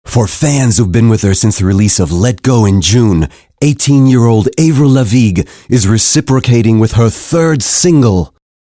englisch (us)
Sprechprobe: Werbung (Muttersprache):